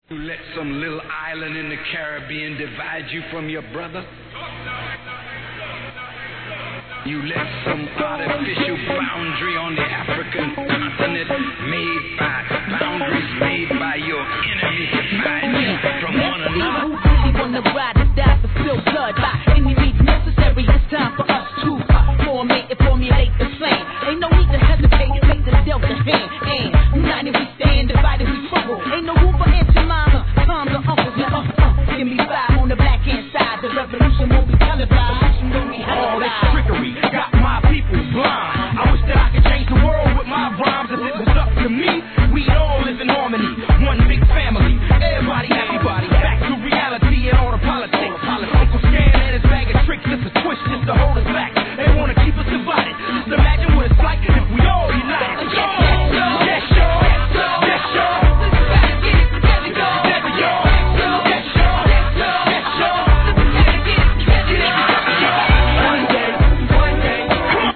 G-RAP/WEST COAST/SOUTH
総勢8人豪華なマイクリレーに注目です！